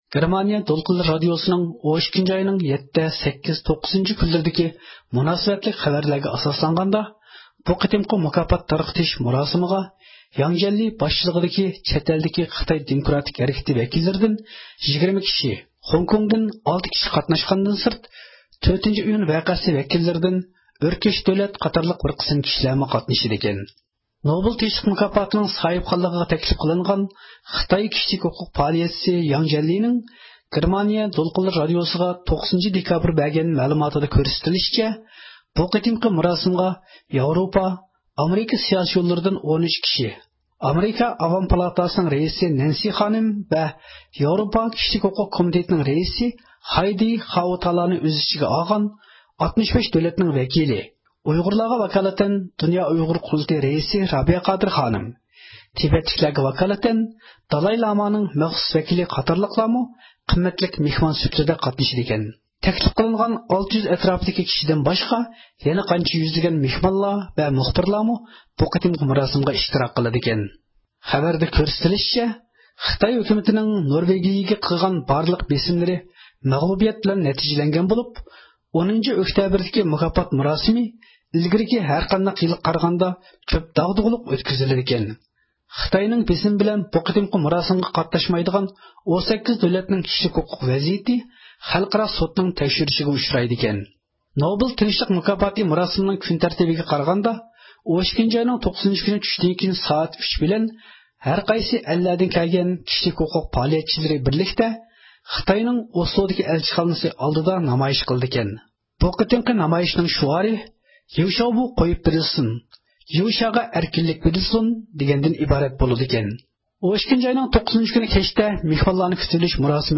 ئىختىيارى مۇخبىرىمىز